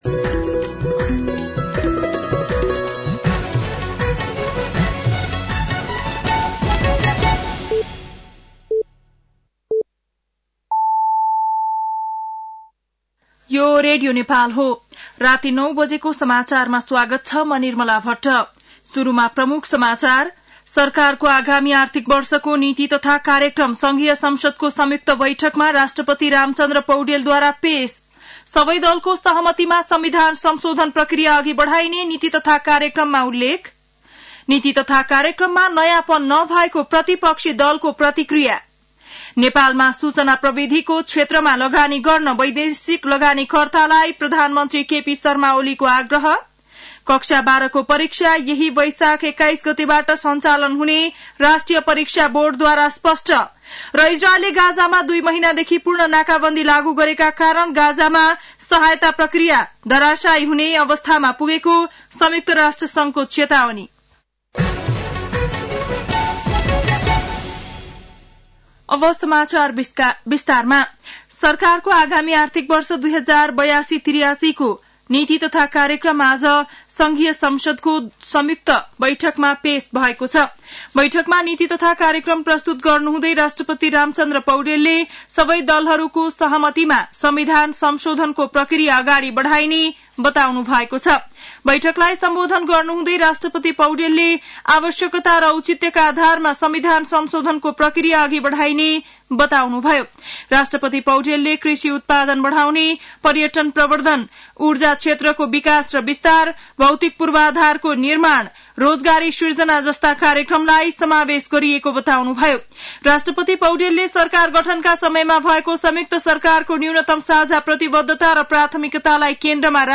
बेलुकी ९ बजेको नेपाली समाचार : १९ वैशाख , २०८२